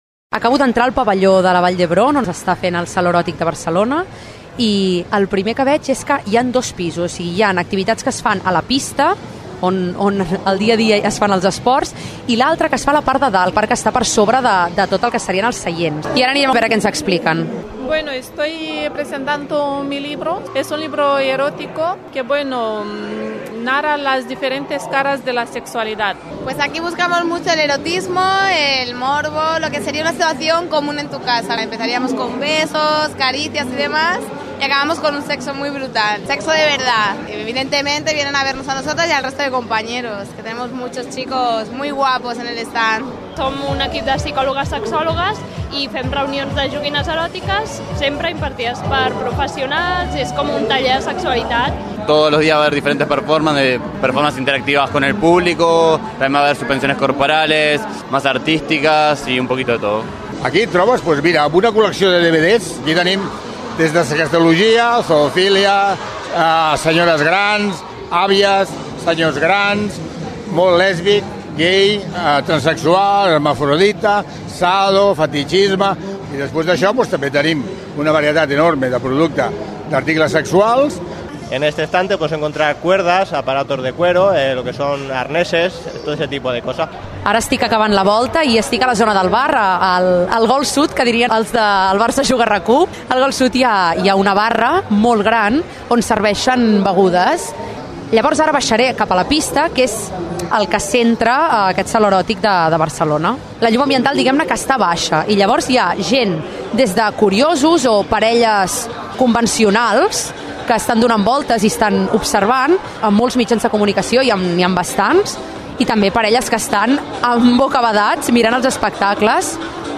Reportatge al Pavelló Olímpic de la Vall d’Hebron de Barcelona on es celebra el Saló Eròtic de Barcelona
Entreteniment